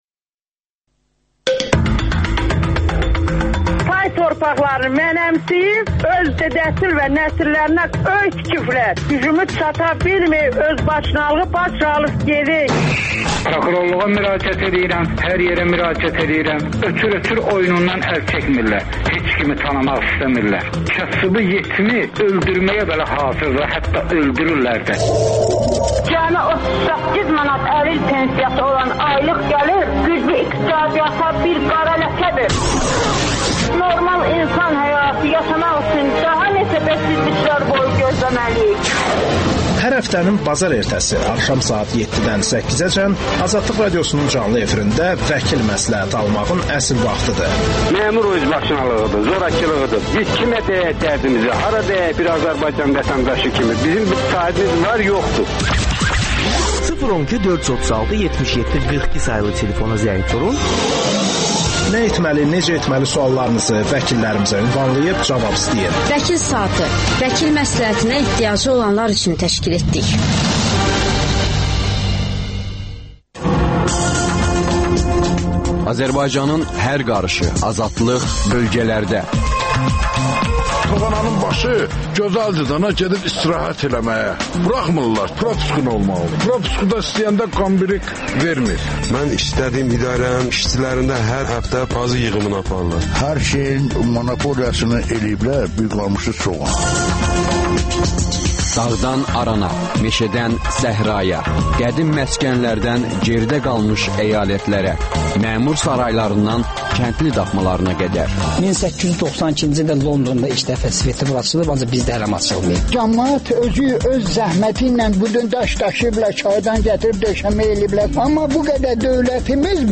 Pen klub - Böyük müzakirələrə səbəb olmuş debatın davamı